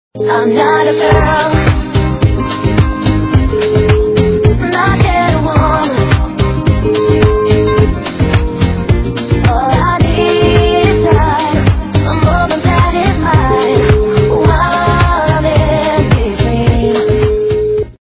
- западная эстрада